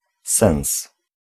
Ääntäminen
Ääntäminen Tuntematon aksentti: IPA: [sens] Haettu sana löytyi näillä lähdekielillä: puola Käännös Ääninäyte Substantiivit 1. sense US 2. meaning US Suku: m .